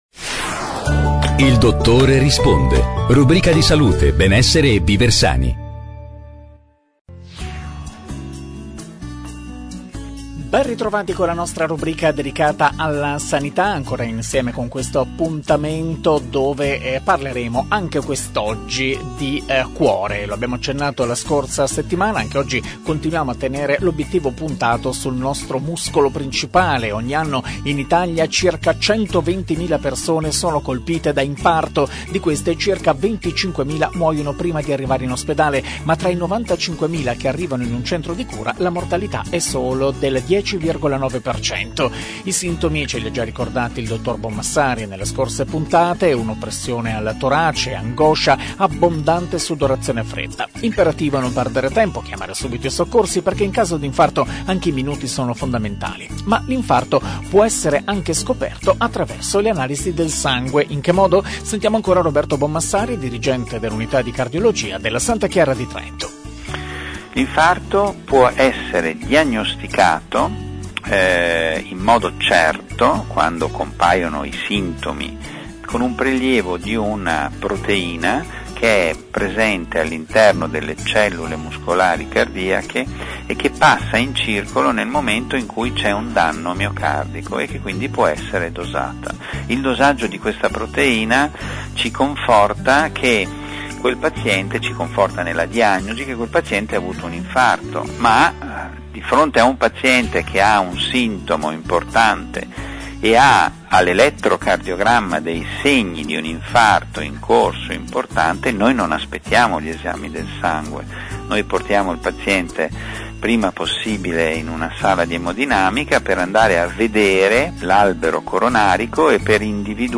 È tornato l’appuntamento settimanale su Radio Dolomiti con medici ed esperti della salute.